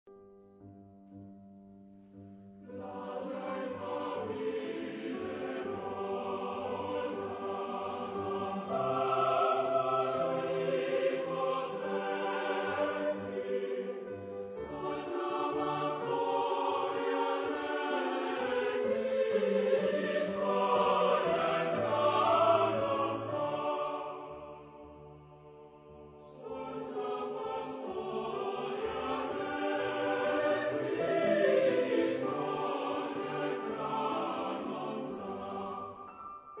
Genre-Style-Forme : Profane ; Romantique ; Pastorale
Caractère de la pièce : pastoral ; vivant ; léger
Type de choeur : SATB  (4 voix mixtes )
Instrumentation : Piano  (1 partie(s) instrumentale(s))
Tonalité : sol majeur